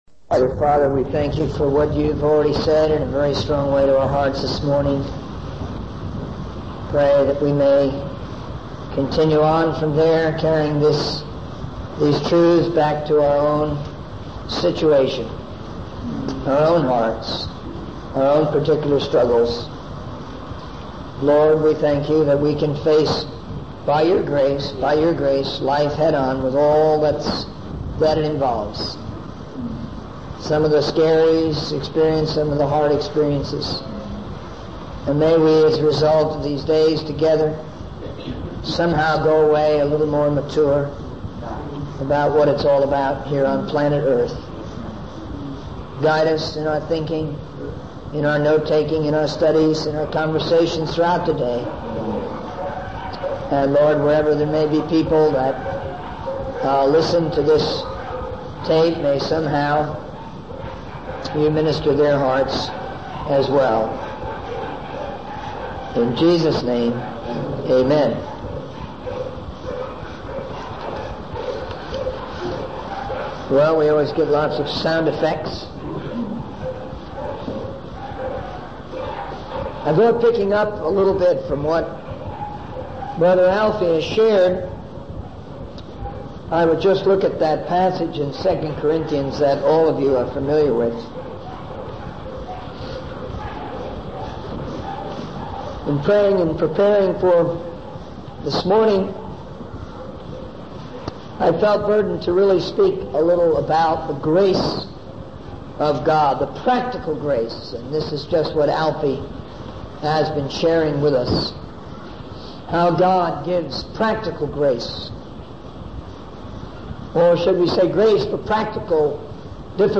In this sermon, the speaker emphasizes the importance of using modern equipment and tools in evangelizing the world.